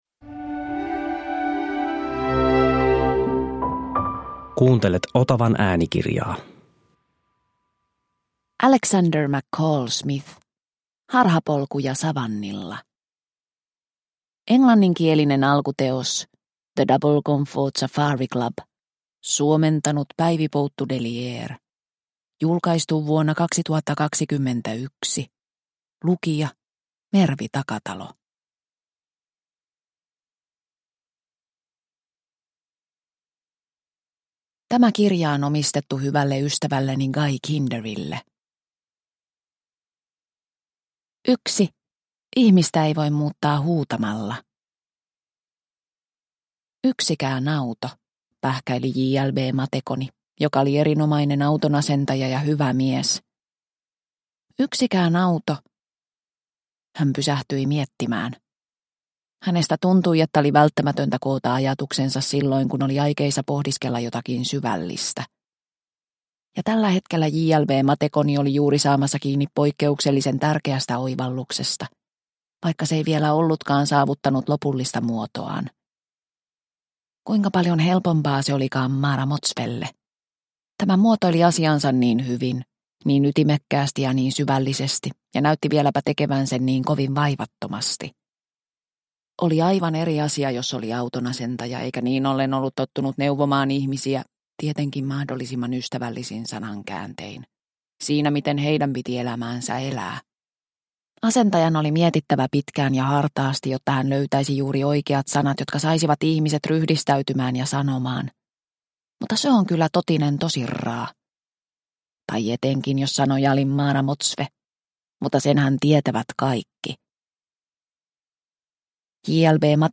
Harhapolkuja savannilla – Ljudbok – Laddas ner